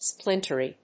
音節splin･ter･y発音記号・読み方splɪ́ntəri
• / splínṭəri(米国英語)
• / ˈsplɪntɜ:i:(英国英語)